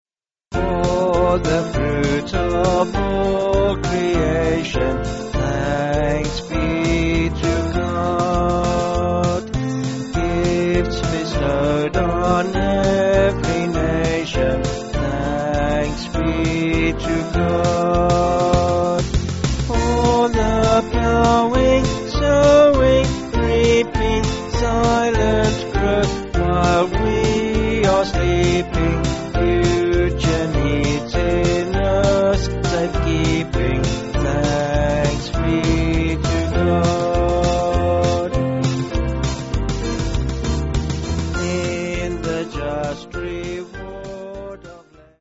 3/F-Gb
Vocals and Band